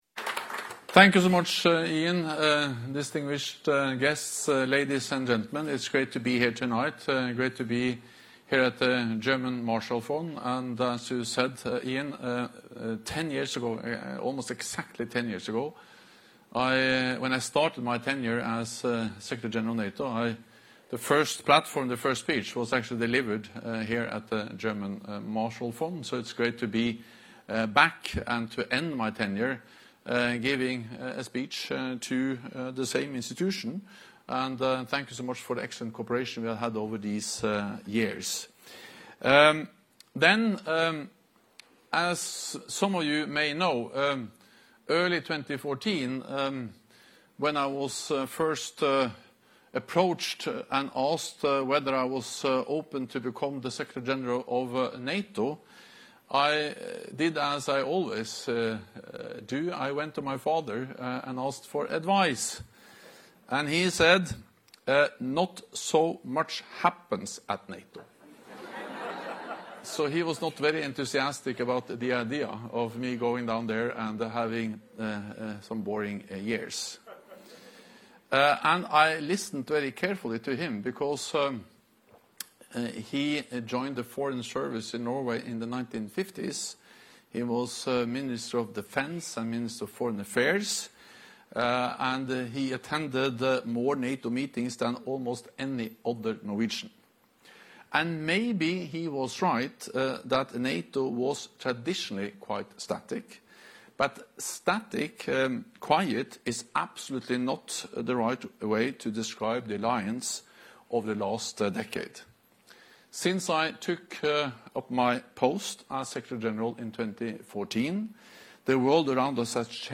Speech and conversation
with NATO Secretary General Jens Stoltenberg at the German Marshall Fund event, Reflections on a Challenging Decade